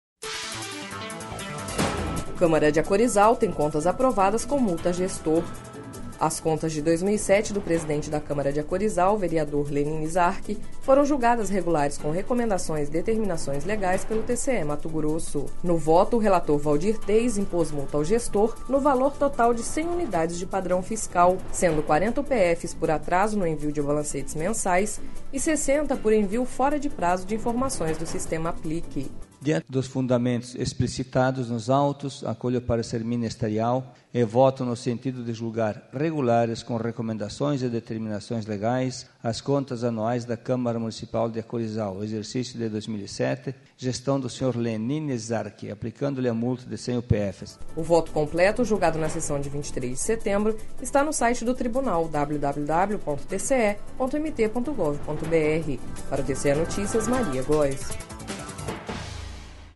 Sonora: Waldir Teis – conselheiro do TCE-MT